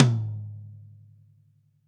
Index of /90_sSampleCDs/ILIO - Double Platinum Drums 1/CD4/Partition E/GRETSCH TOMD